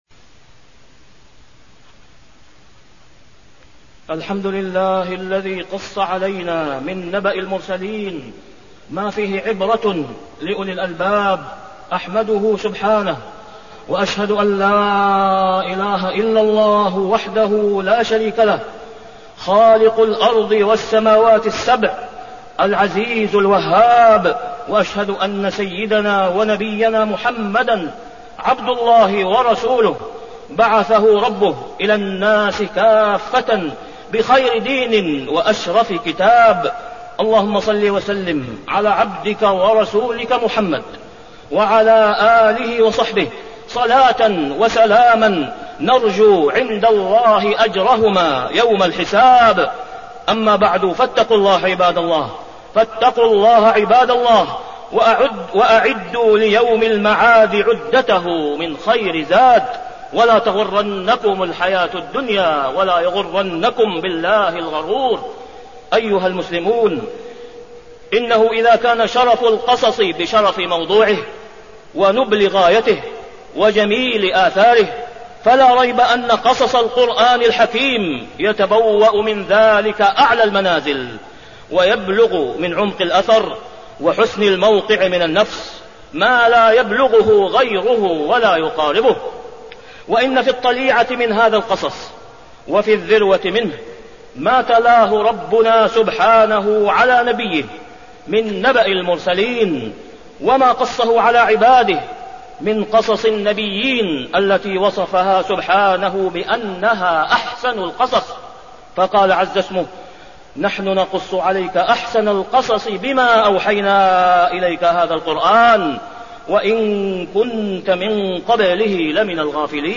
تاريخ النشر ١٩ جمادى الآخرة ١٤٣٠ هـ المكان: المسجد الحرام الشيخ: فضيلة الشيخ د. أسامة بن عبدالله خياط فضيلة الشيخ د. أسامة بن عبدالله خياط دروس وعبر من قصص القرآن